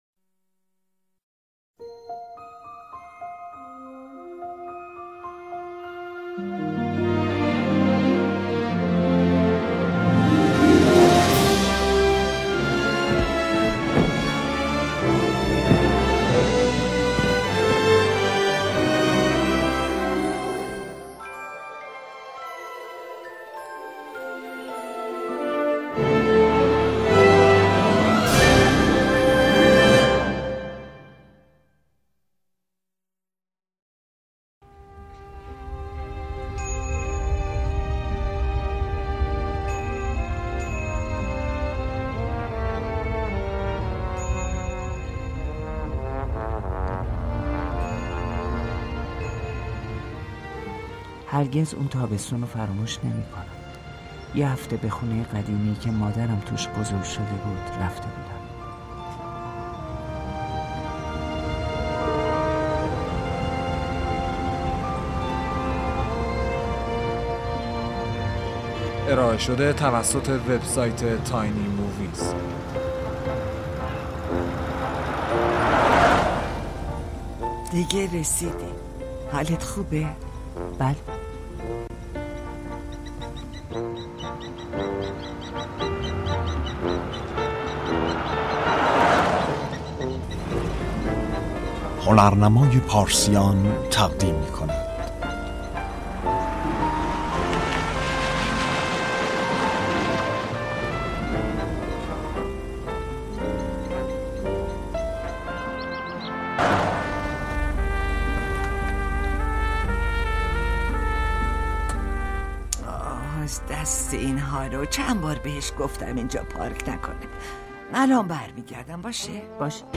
دانلود مستقیم و رایگان انیمیشن دنیای بندانگشتی با دوبله فارسی The Secret World of Arrietty 2010 دانلود دوبله فارسی انیمیشن دنیای بندانگشتی